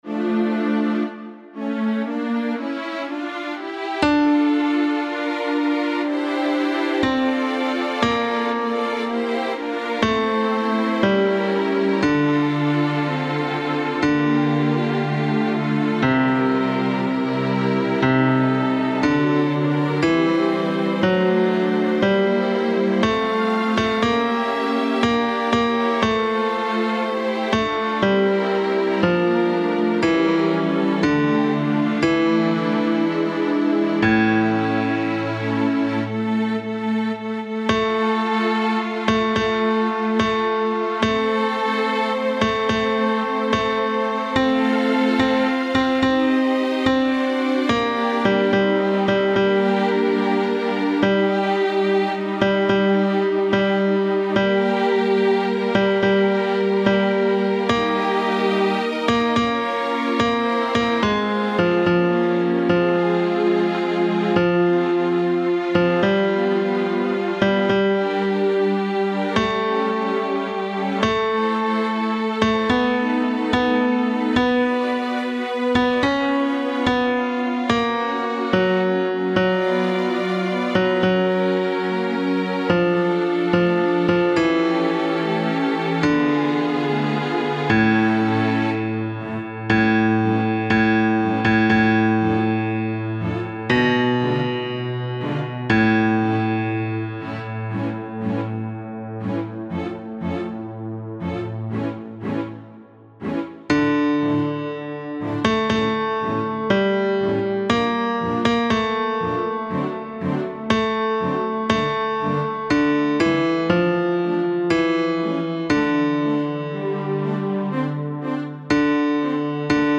Bajo I
6.-Libera-me-BAJO-I-MUSICA.mp3